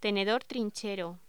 Locución: Tenedor trinchero
voz